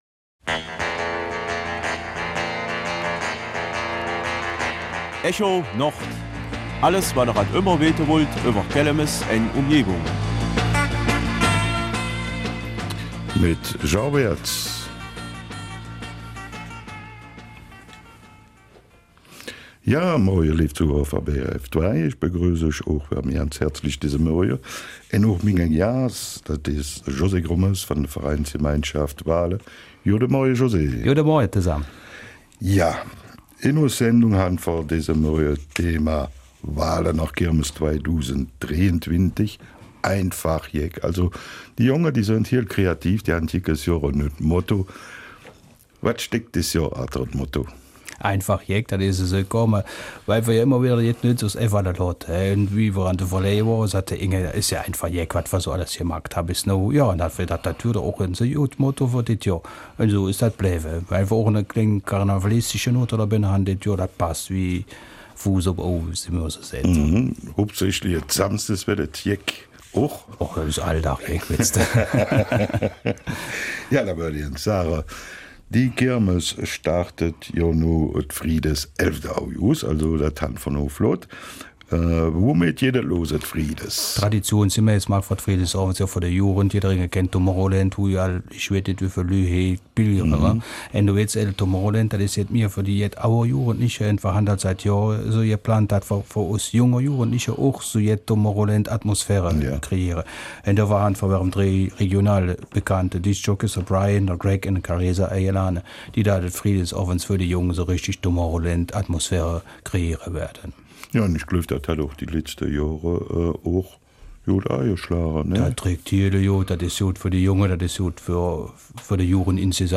Kelmiser Mundart: ''Walhorner Kirmes 2023 einfach jeck!''